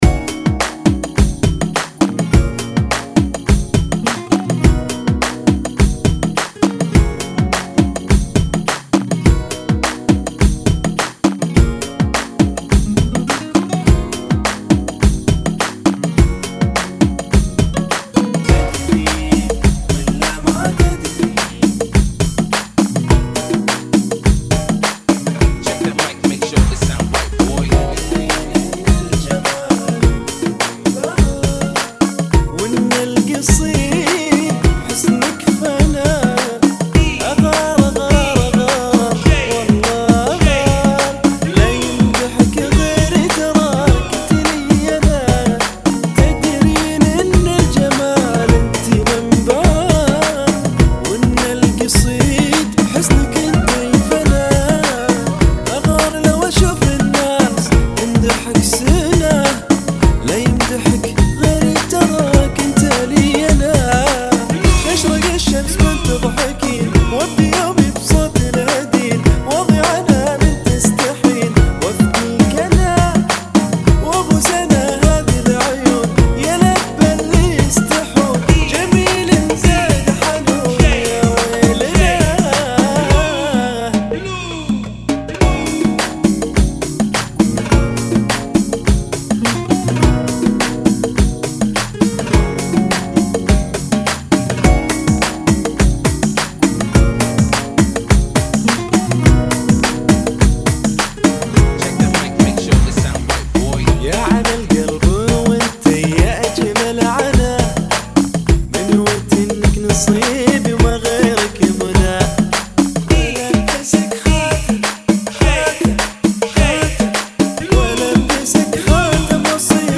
82 Bpm